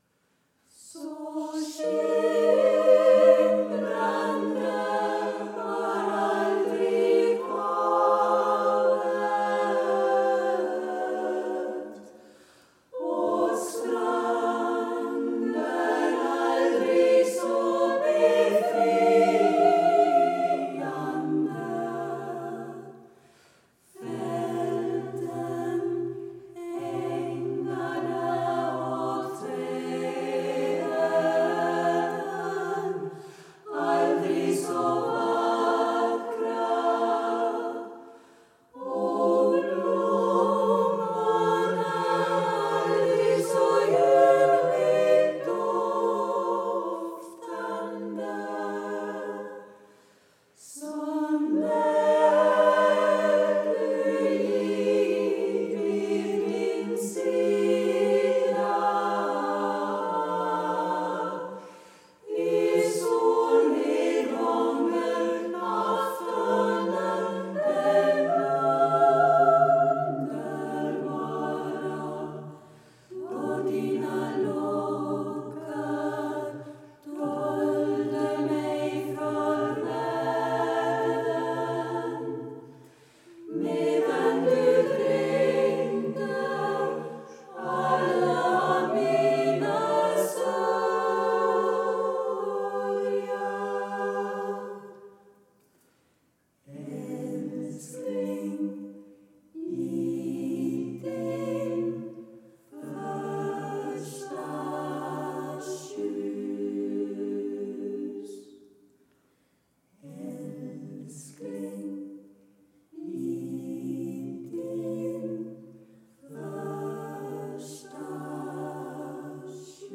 Klang ud over det sædvanlige, leg med skæve rytmer
og virtuos sang, fra den dybeste alt-klang
til den højeste  soprantone –